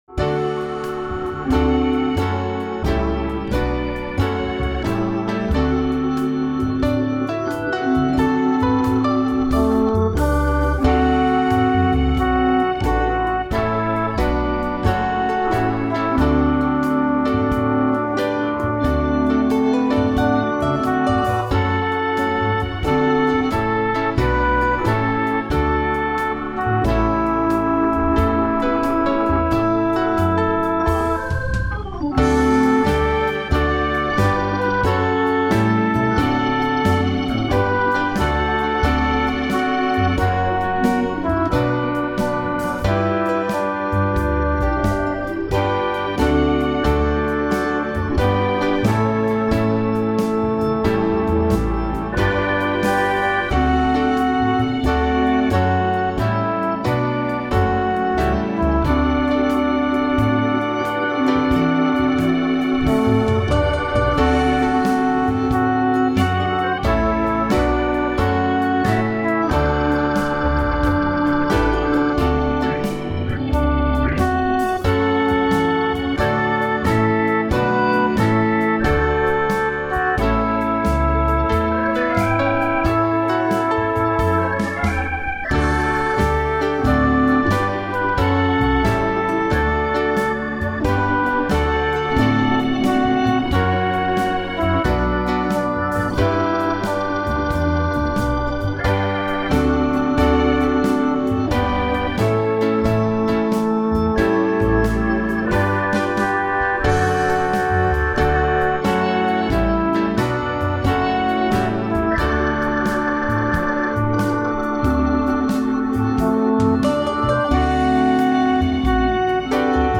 This is a traditional African American song, suitable for Good Friday services if you can handle it.
My backing is at 90bpm and just goes.